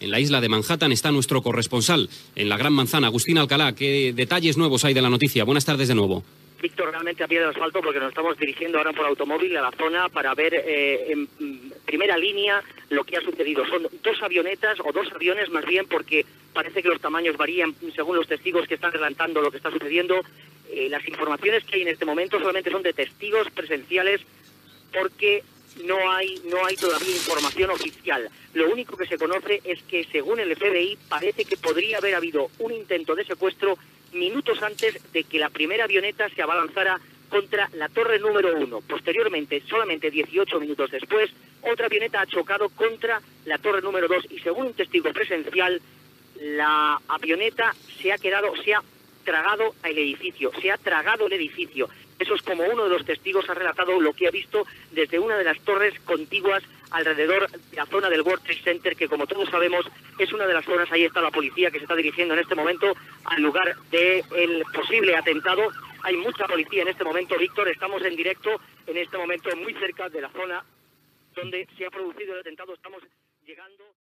Primera crònica des de Nova York dels atemptats amb avions a les torres bessones del World Trade Center.
Informatiu